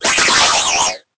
Cri_0843_EB.ogg